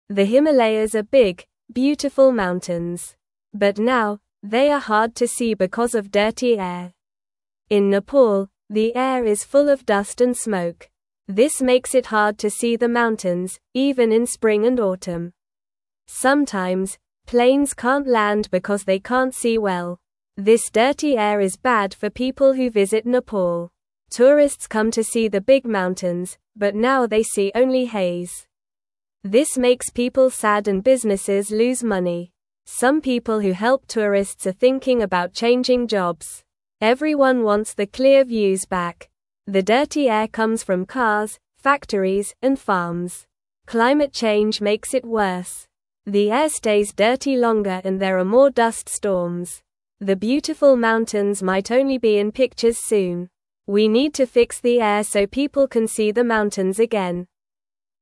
Normal
English-Newsroom-Beginner-NORMAL-Reading-Dirty-Air-Hides-Beautiful-Himalayas-from-Everyone.mp3